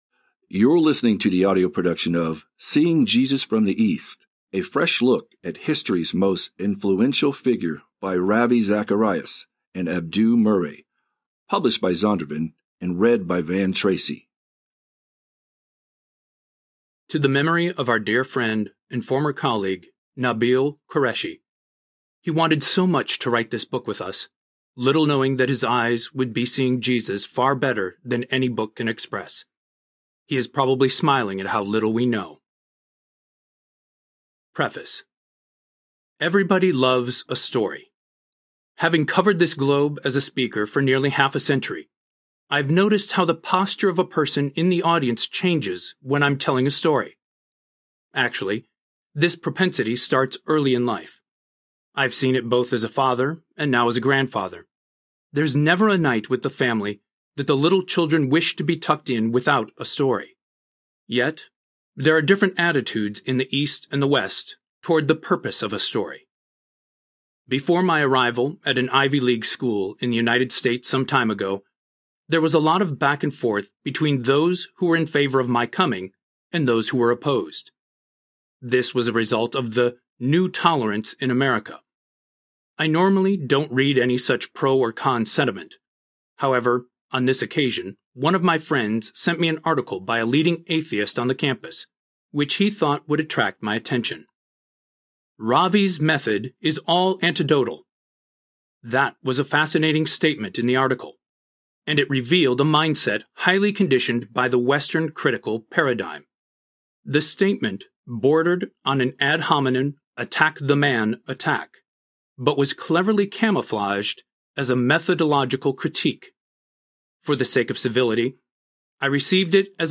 Seeing Jesus from the East Audiobook
Narrator
6.9 Hrs. – Unabridged